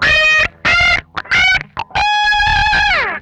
MANIC RISE 1.wav